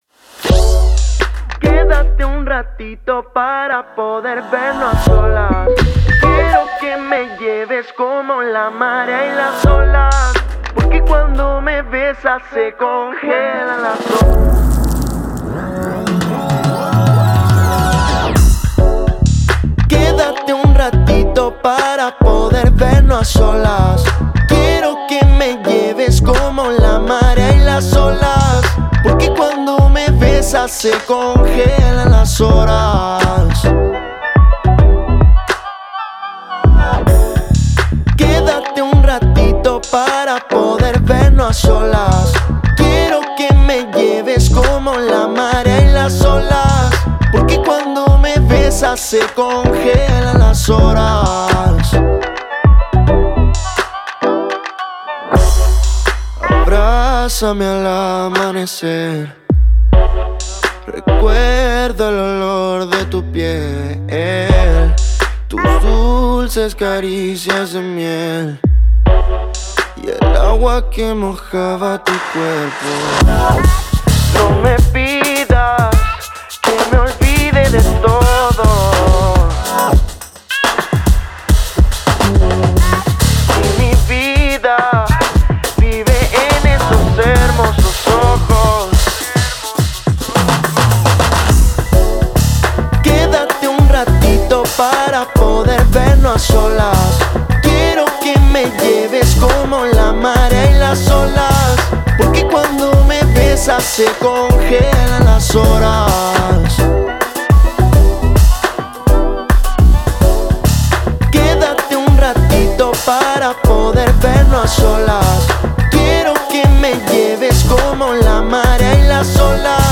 baterista
El nuevo tema, es una fusión entre el soul, jazz, hip hop.
bajo
teclas